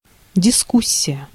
Ääntäminen
Synonyymit talks discourse agitate discuss contest contend Ääntäminen US UK : IPA : /dɪˈbeɪt/ Lyhenteet ja supistumat (laki) Deb.